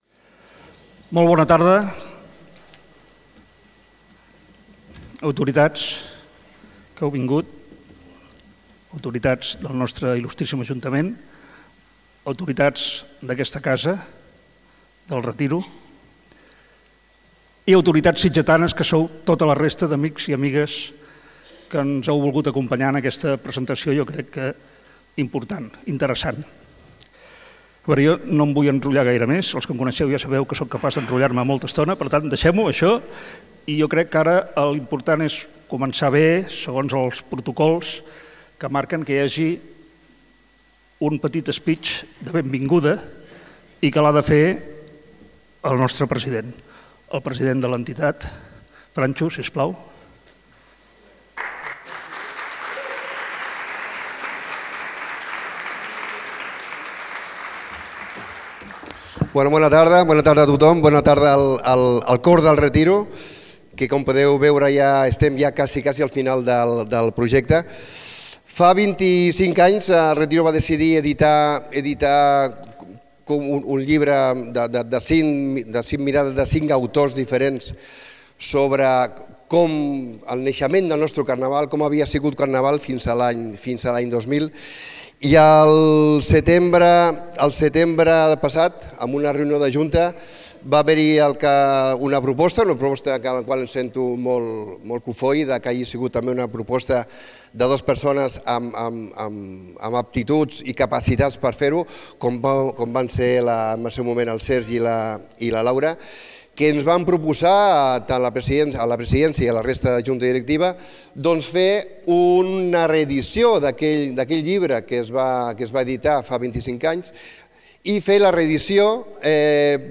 Us oferim l’acte íntegre.